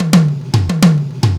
TOM FILL 1-L.wav